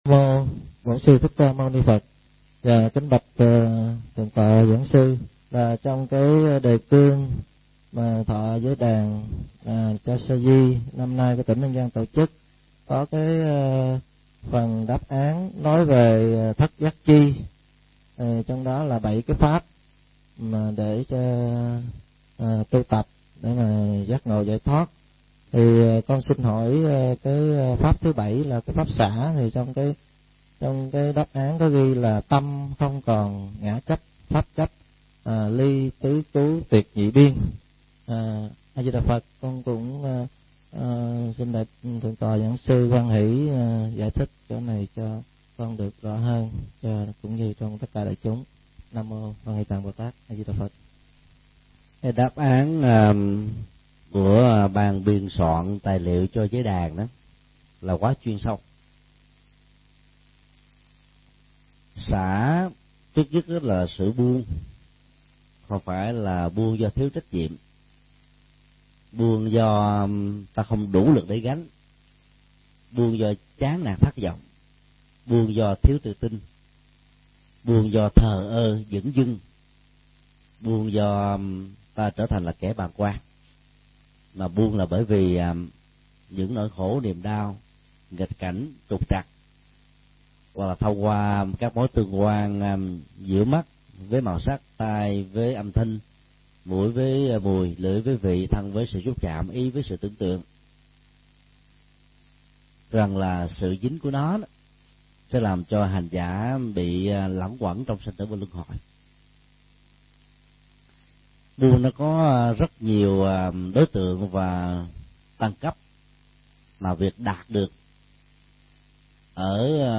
Nghe mp3 Vấn đáp: Ý nghĩa và thực tập buông xã